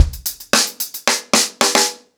TupidCow-110BPM.7.wav